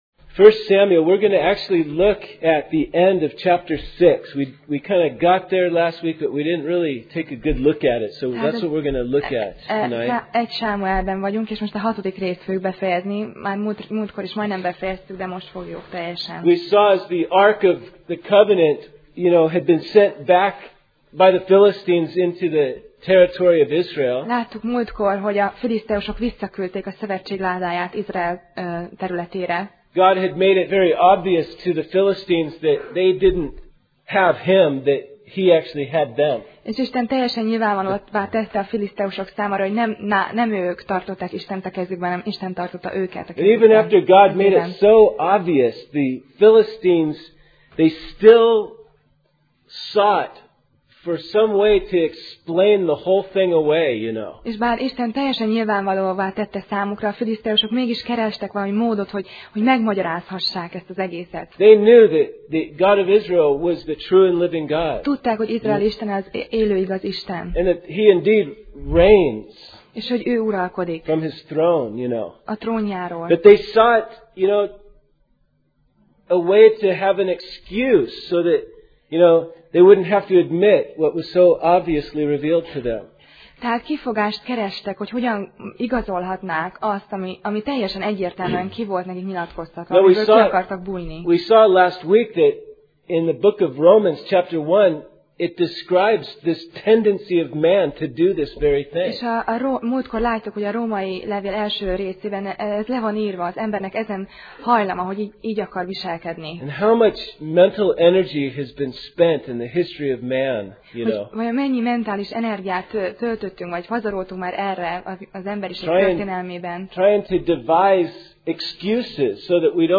1Sámuel Passage: 1Sámuel (1Samuel) 6:19-21 Alkalom: Szerda Este